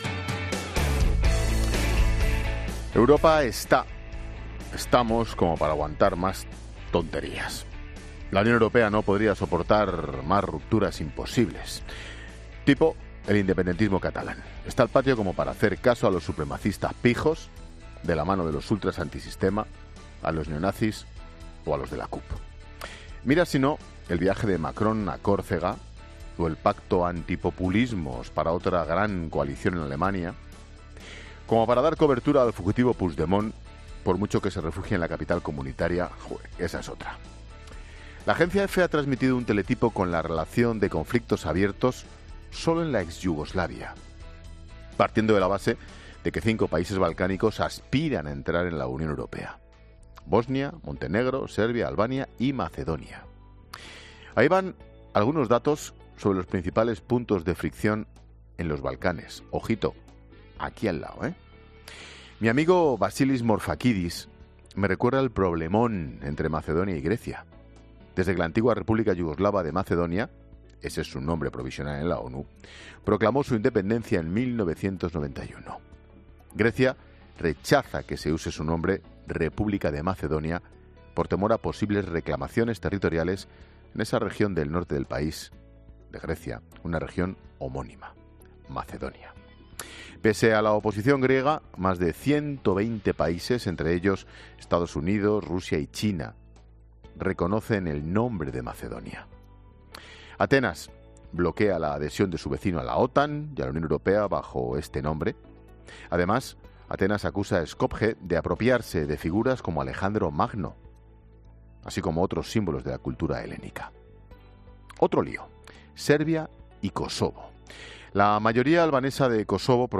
AUDIO: El comentario de Ángel Expósito.
Monólogo de Expósito